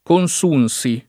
consumere [konS2mere] v.; consumo [konS2mo] — pass. rem. consunsi [